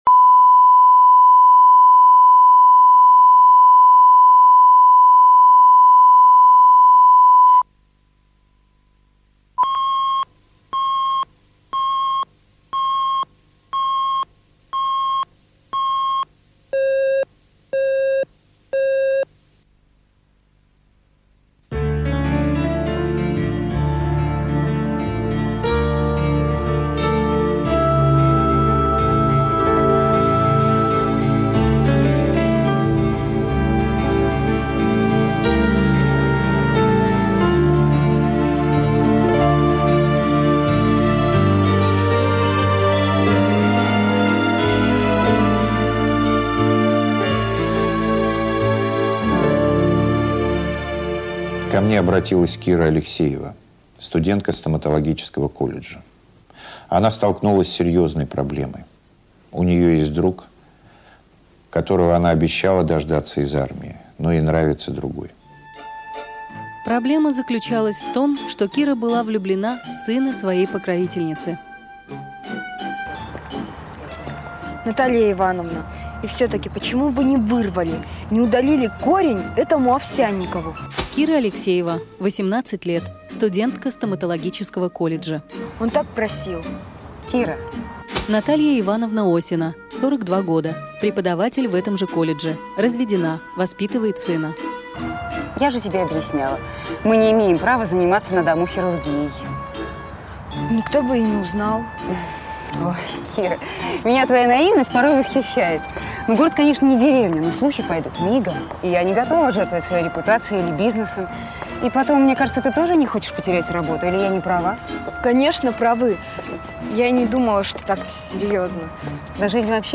Аудиокнига Золушка